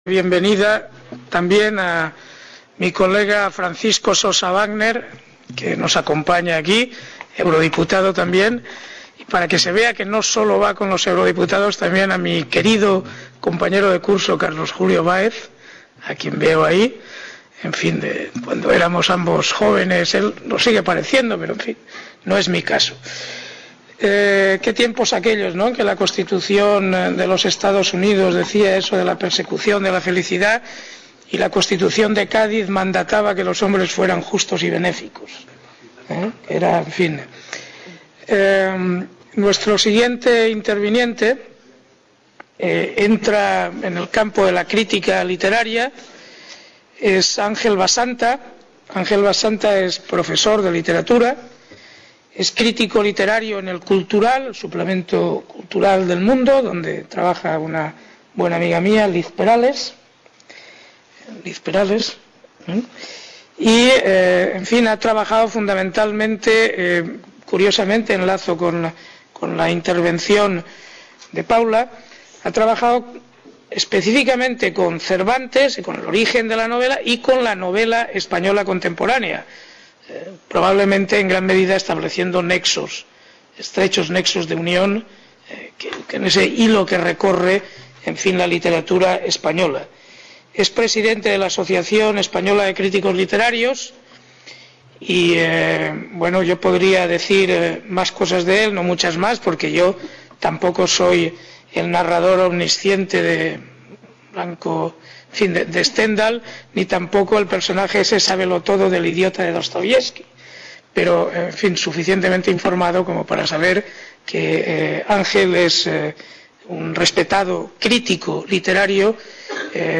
Mesa redonda: Literatura española y Europa | Repositorio Digital
Universidad | Red: UNED | Centro: UNED | Asig: Reunion, debate, coloquio...